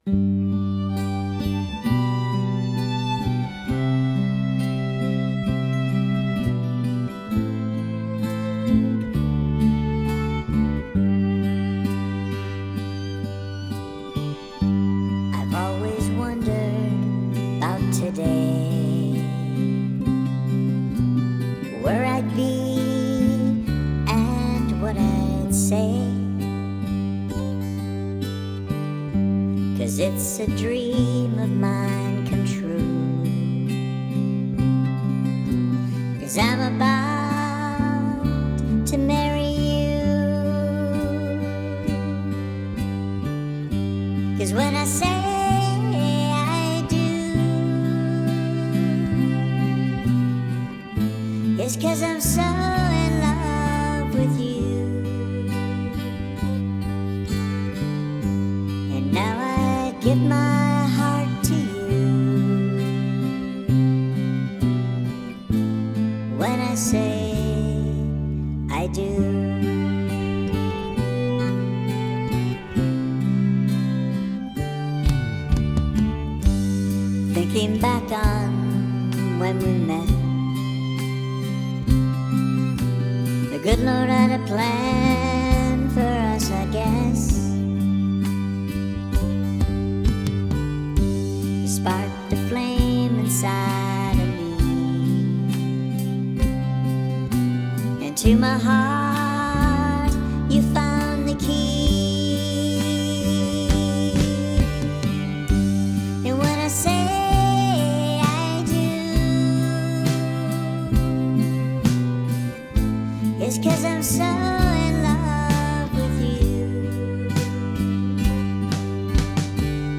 Key of G - Track with Reference Vocal